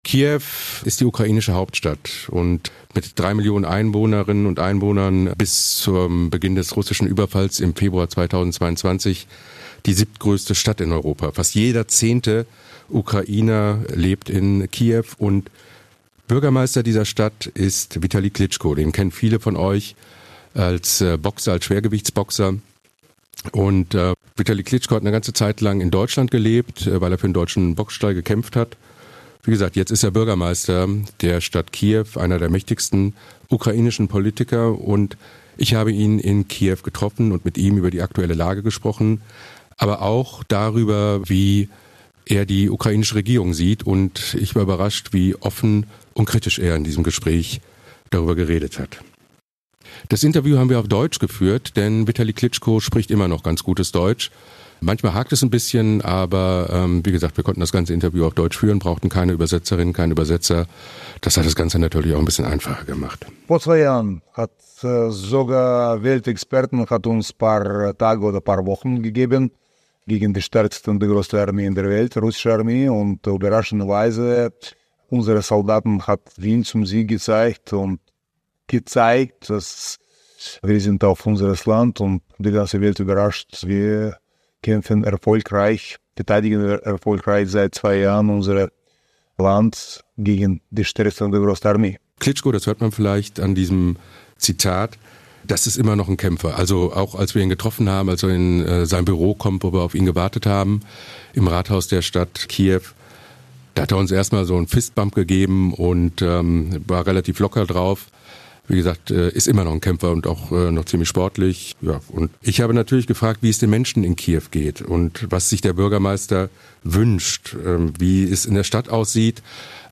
Interview mit Klitschko: Funkstille mit Selenskyj ~ Im Krisenmodus Podcast